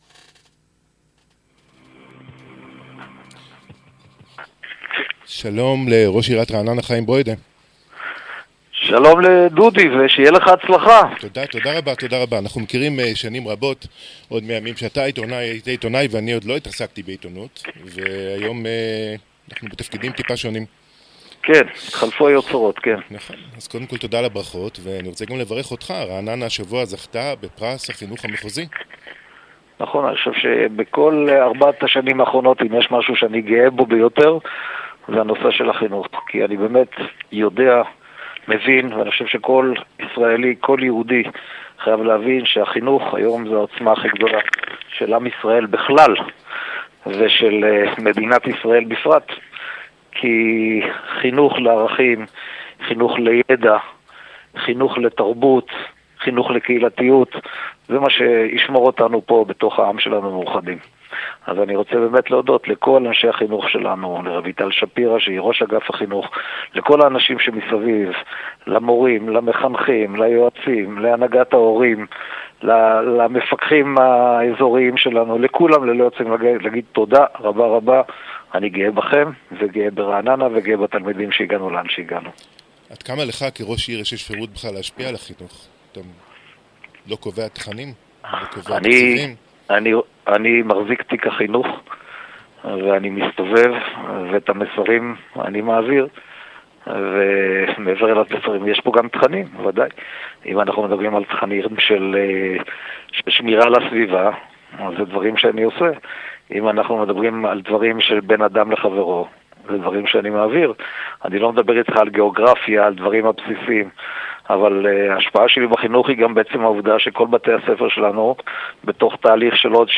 ראיון עם ראש עיריית רעננה חיים ברוידא 17.11.22 - רעננה ניוז